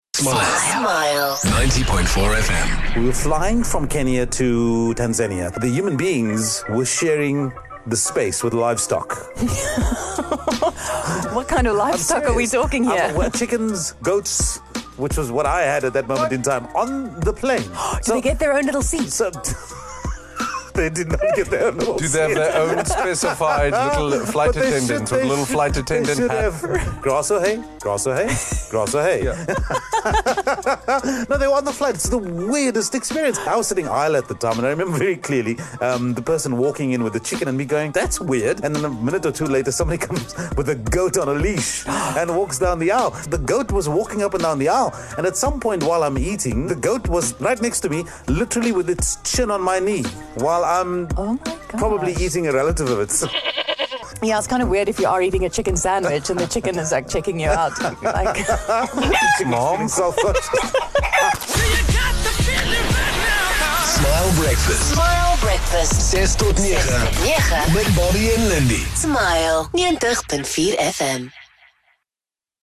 Smile Breakfast had a conversation about some weird experiences on flights.